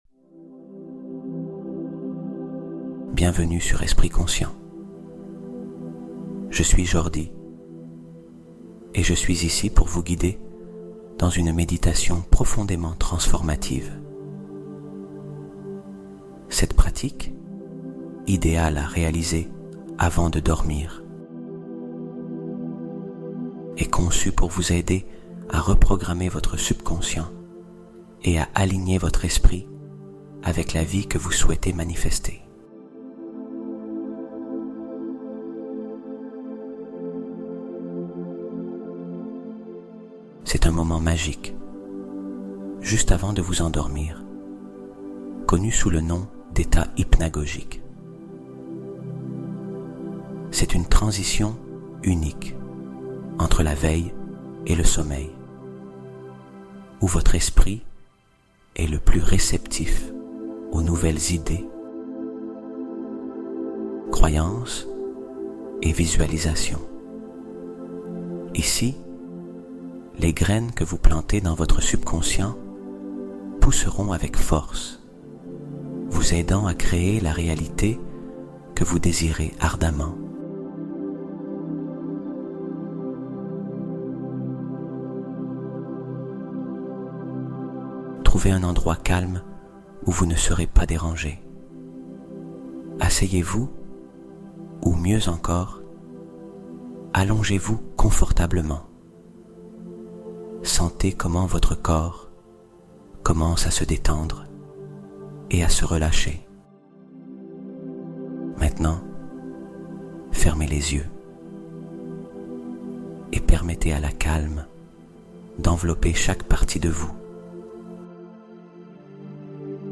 5 Minutes Avant de Dormir Pour Changer Ta Réalité Complètement : Méditation Guidée Révolutionnaire